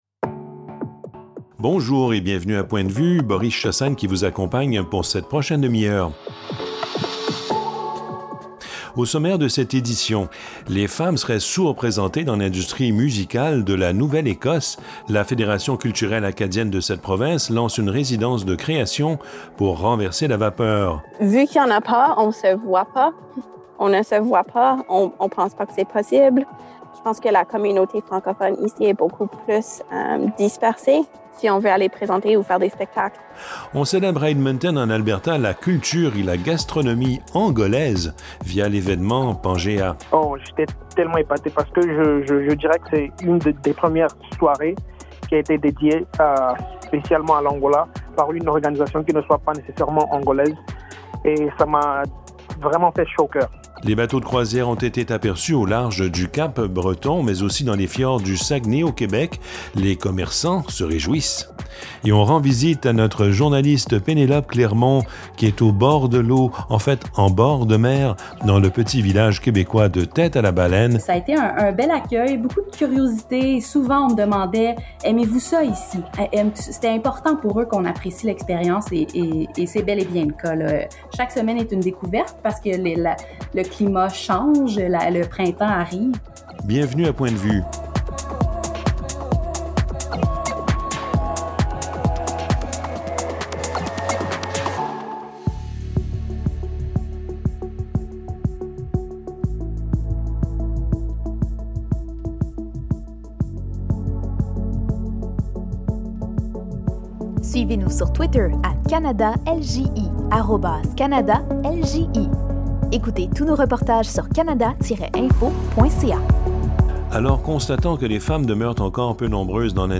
Points de vue, épisode 13 Points de vue, en ondes sur une quarantaine radios francophones canadiennes.